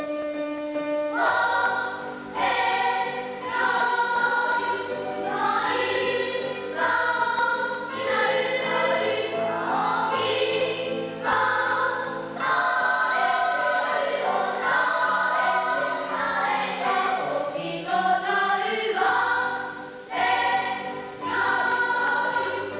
学級全員による合唱です。
聞かせどころだけを録音しました。
もとは体育館でステレオ録音したものです。
美しく青きドナウ ４部合唱 堀内敬三 ヨハンシュトラウス あります